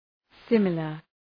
Προφορά
{‘sımələr}